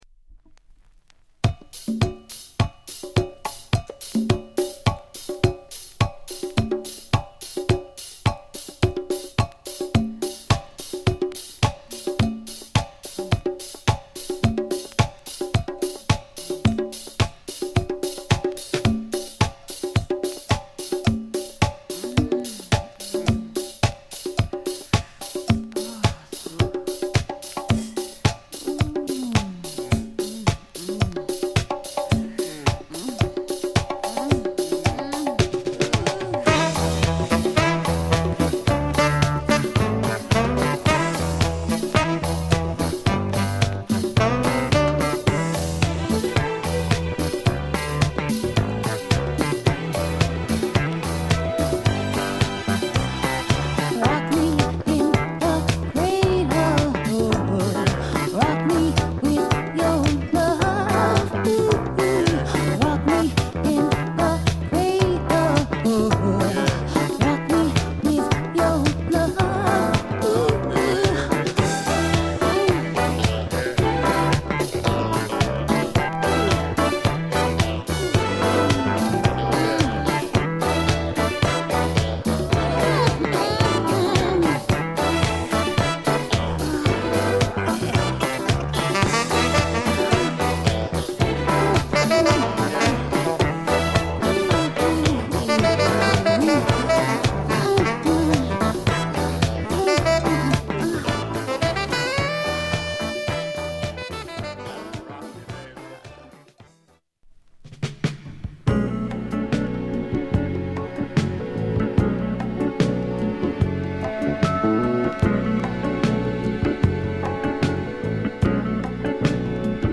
時代もあってか全体的にDisco寄りのサウンド・プロダクションで
抜けの良いディスコブレイクから幕開けるグルーヴィーなセミ・インスト・ディスコのB2
また女性ヴォーカルをフィーチャーしたレジーなメロウA3